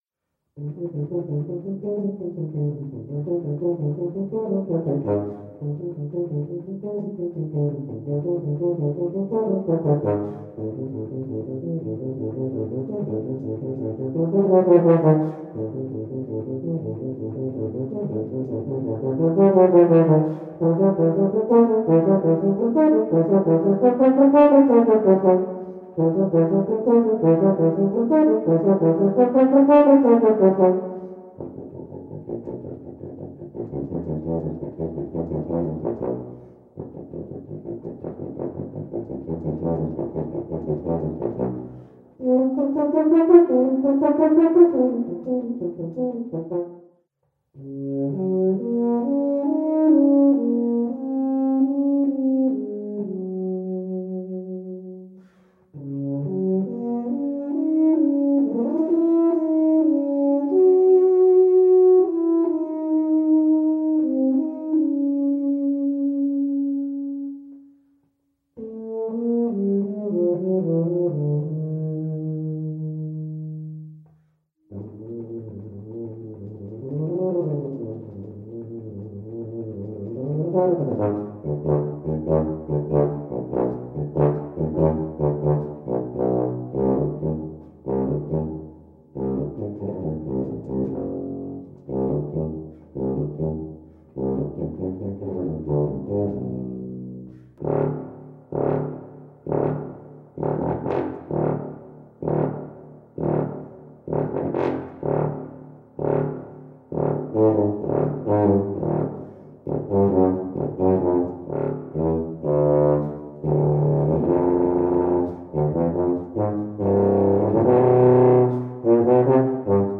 Voicing: Tuba Solo